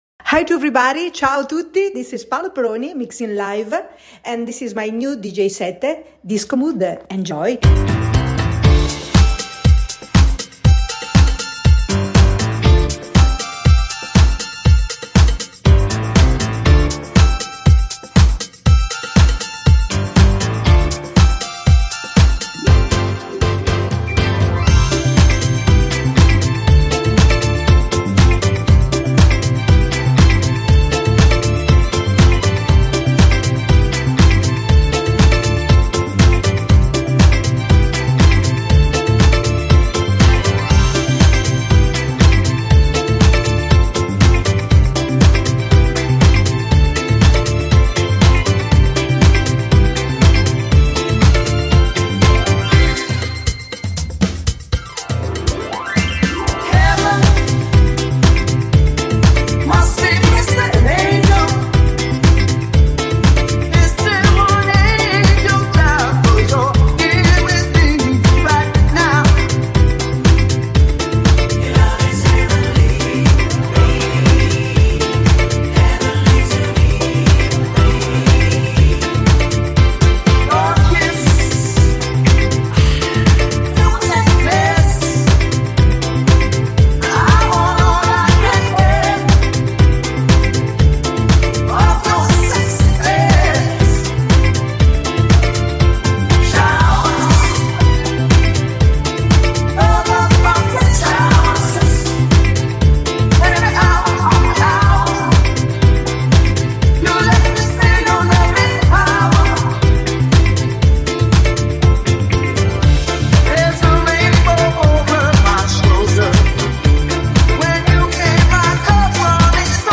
recorded live in Madonna di Campiglio